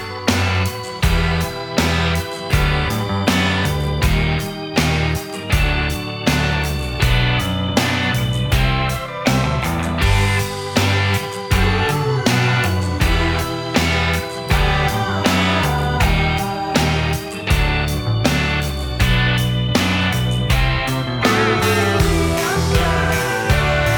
no Backing Vocals Indie / Alternative 2:44 Buy £1.50